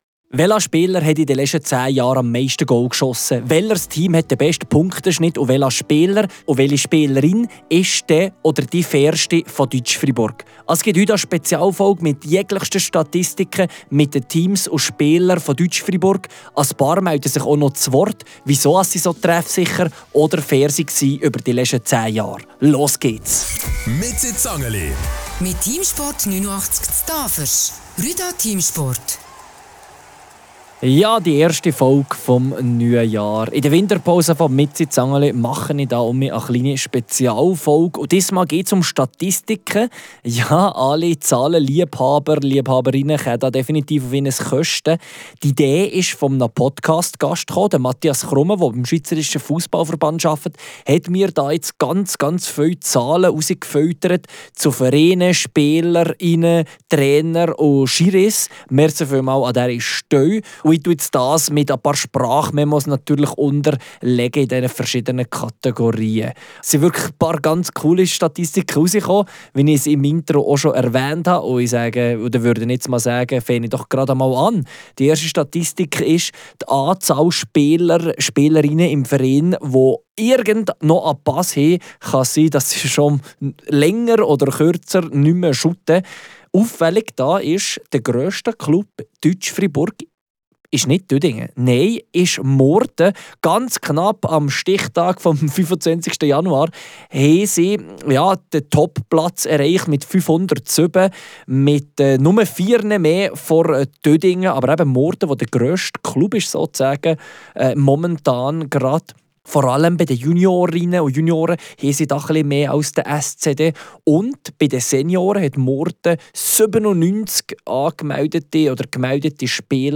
Die neue Spezialfolge während der Winterpause widmet sich vor allem den Zahlen, angereichert mit Stimmen der Beteiligten.